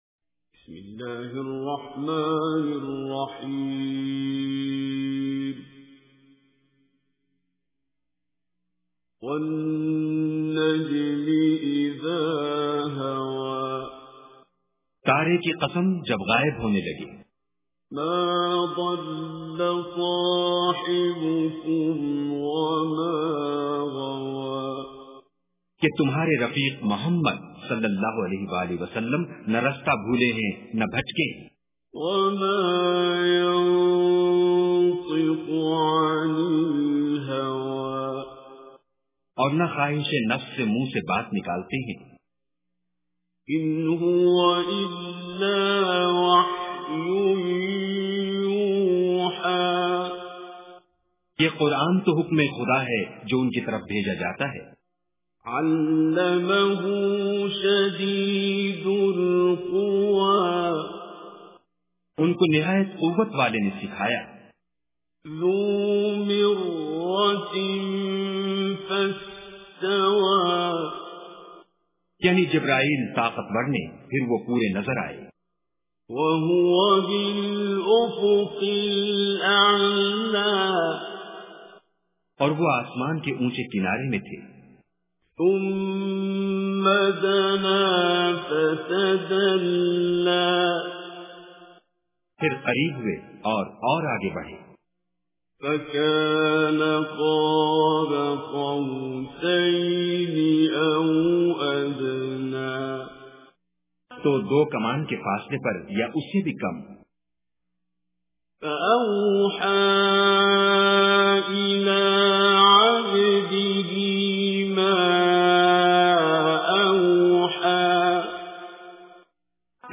Surah An-Najm Recitation with Urdu Translation
Surah An Najm is 53rd chapter of Holy Quran. Listen online and download mp3 tilawat / recitation of Surah An Najm in the voice of Qari Abdul Basit As Samad.